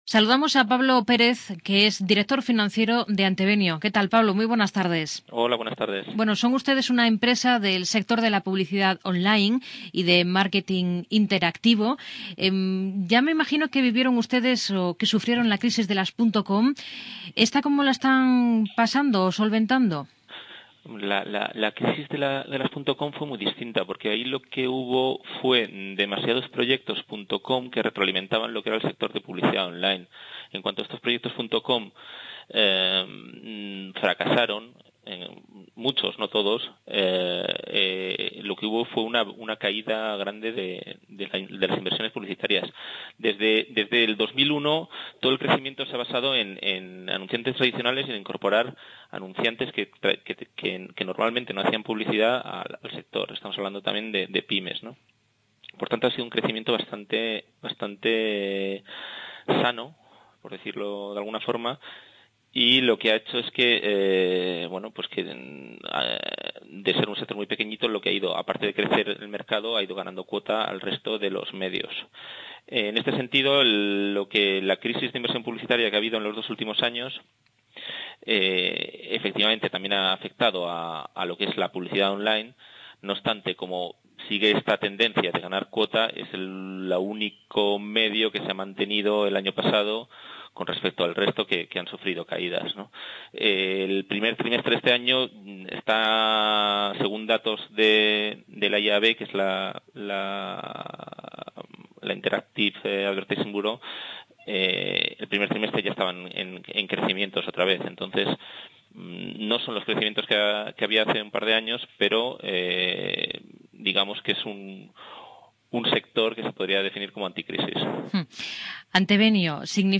entrevista-antevenio-Cope2.mp3